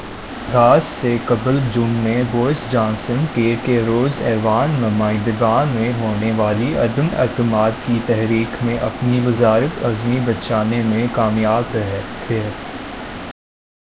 Spoofed_TTS/Speaker_07/260.wav · CSALT/deepfake_detection_dataset_urdu at main